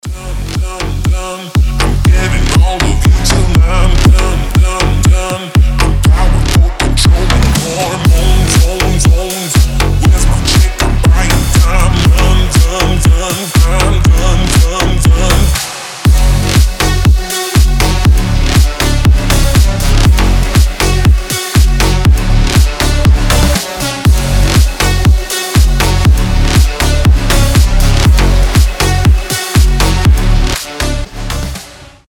жесткие
мощные басы
Brazilian bass
взрывные
slap house